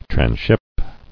[tran·ship]